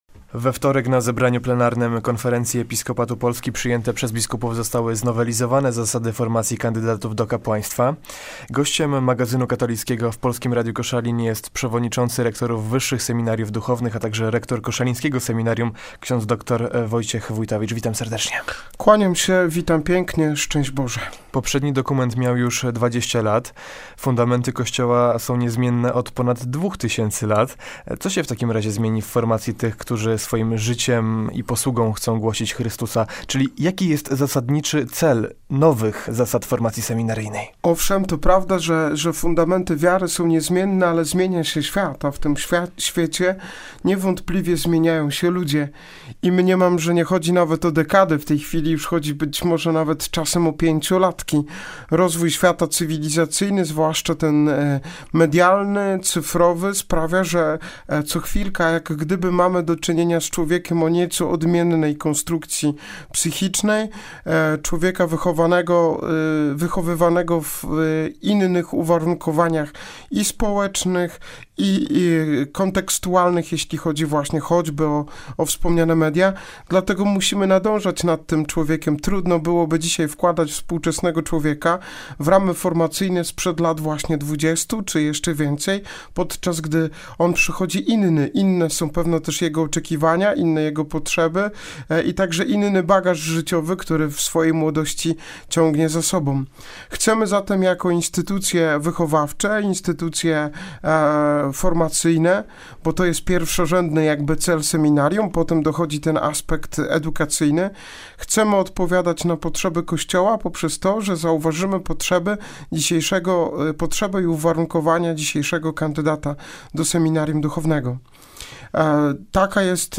Nagranie dzięki uprzejmości Polskiego Radia Koszalin.